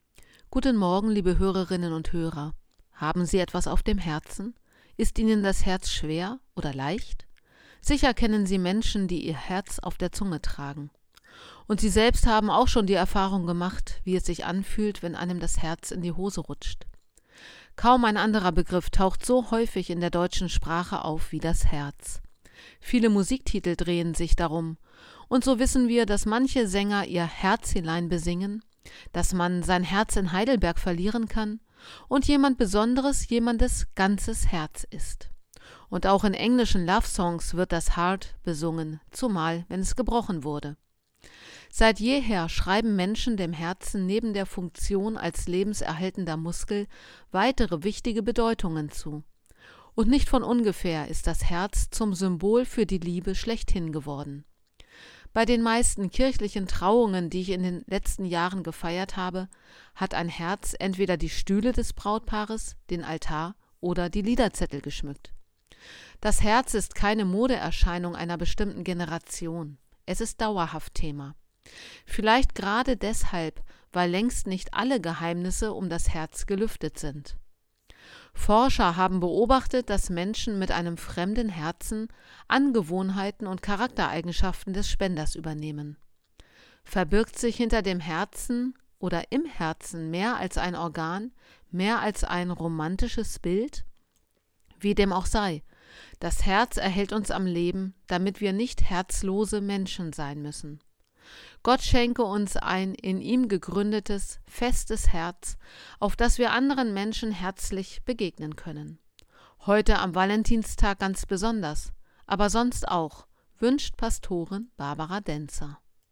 Radioandacht vom 14. Februar